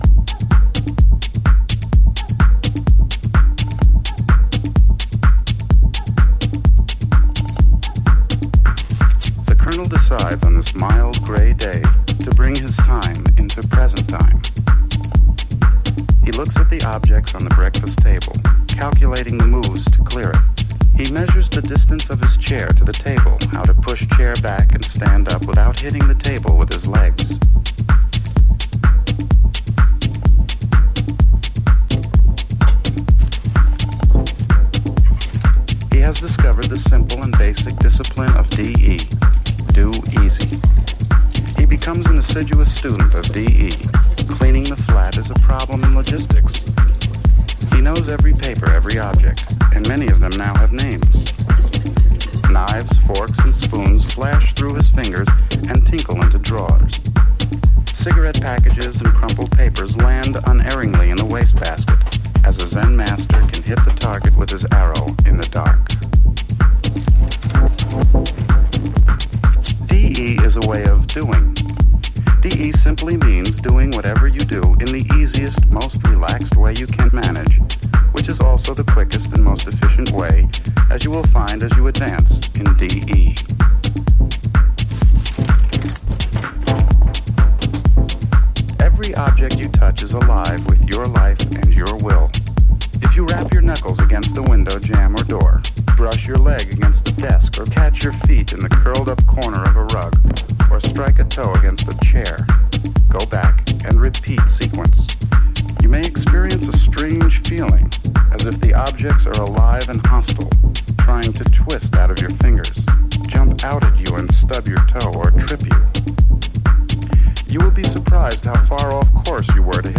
House / Techno
ややハウスグルーヴのSIDE-A。